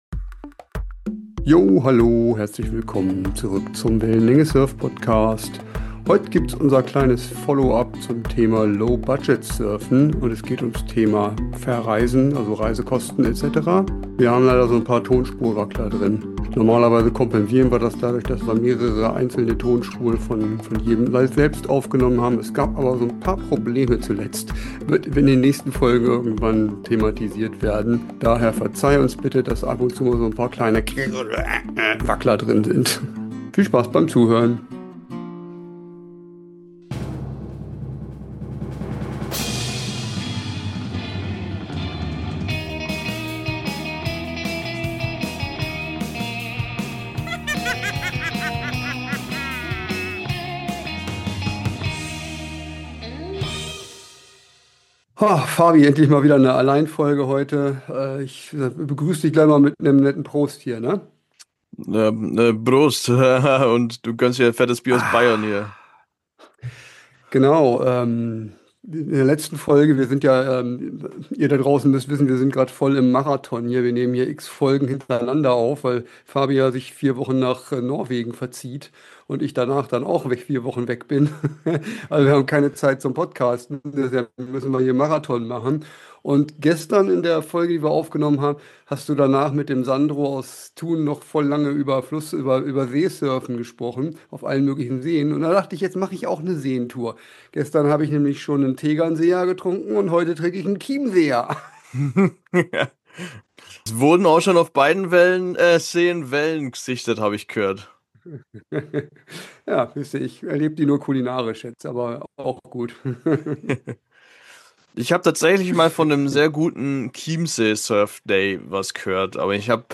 Die Stimmung ist top, der Ernst hält sich in Grenzen...